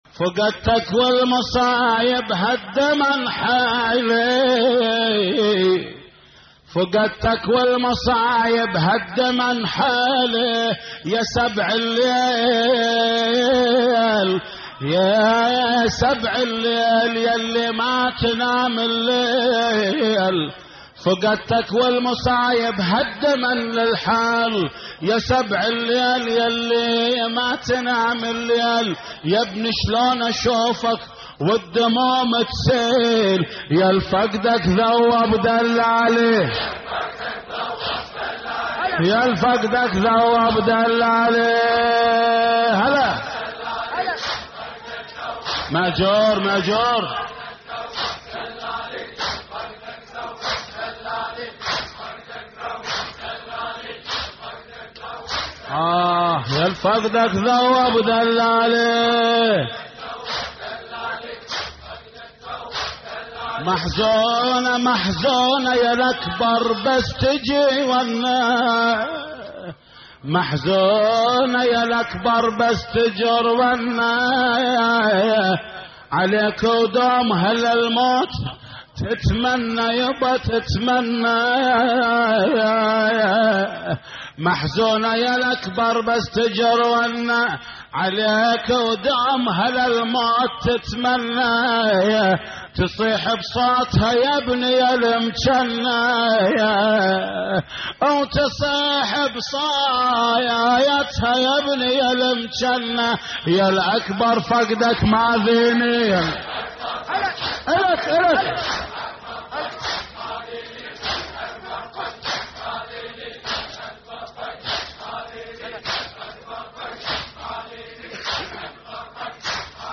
هوسات لحفظ الملف في مجلد خاص اضغط بالزر الأيمن هنا ثم اختر